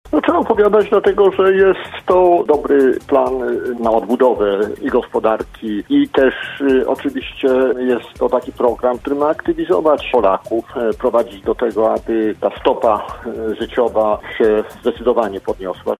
Marek Ast, prezes lubuskich struktur Prawa i Sprawiedliwości, który był gościem „Rozmowy po 9”, powiedział, że to ważne, by o programie dowiedziało się jak najwięcej Polaków: